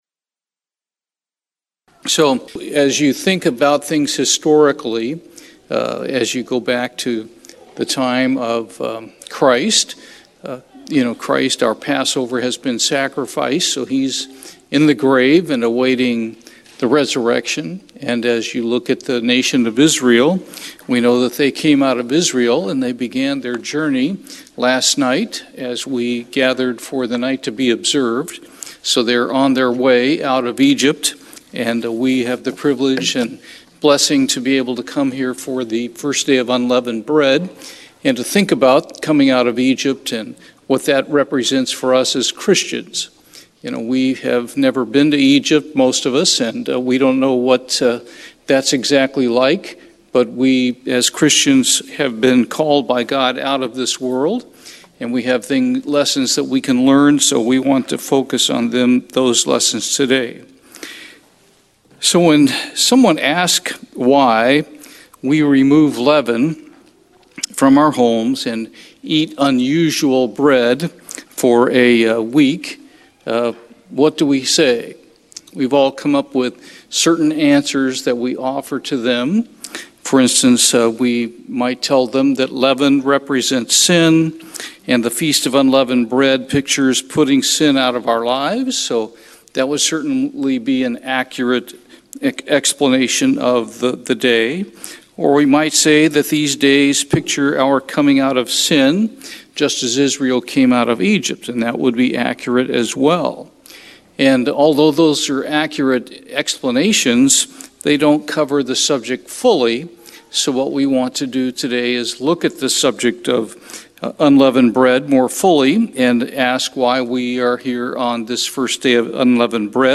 Sermons
Given in Houston, TX